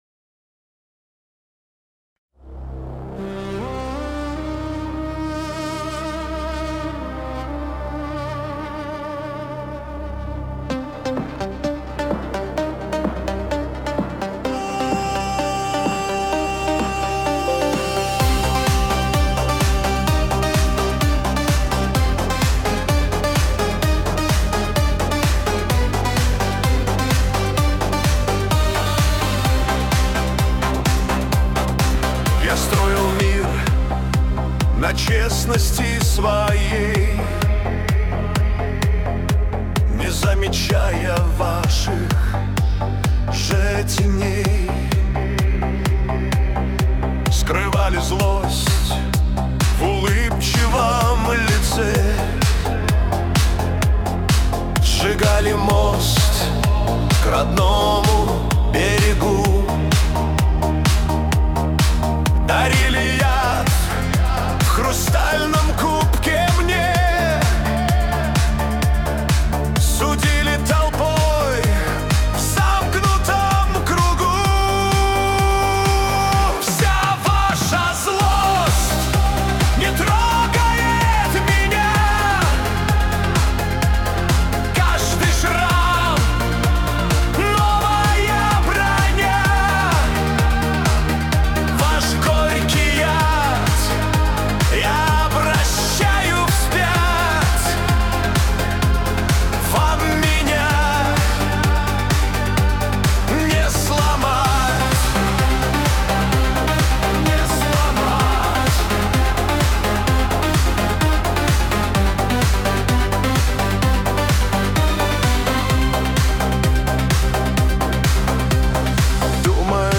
Качество: 320 kbps, stereo
Нейросеть Песни 2025, Стихи